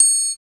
39 TRIANGLE.wav